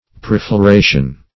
Search Result for " prefloration" : The Collaborative International Dictionary of English v.0.48: Prefloration \Pre`flo*ra"tion\, n. [Pref. pre- + L. flos, floris, flower.] (Bot.) Aestivation.